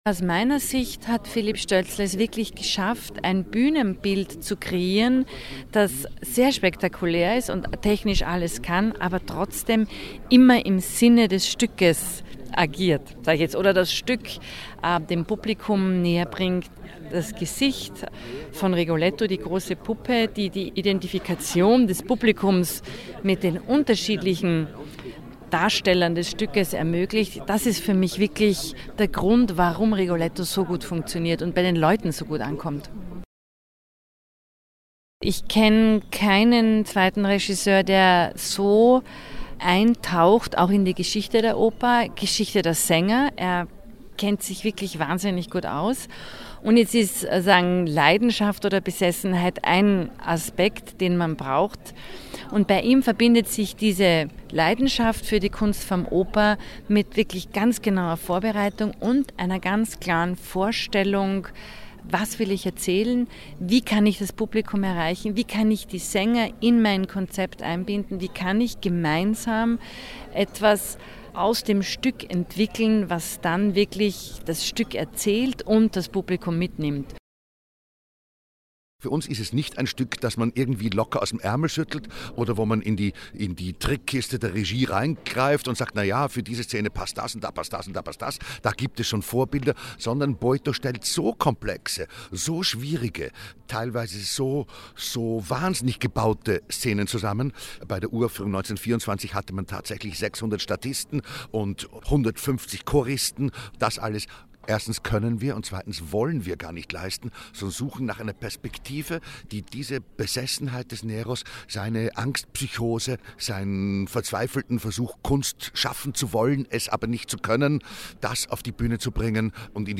Pressekonferenz Festspielprogramm 2020 feature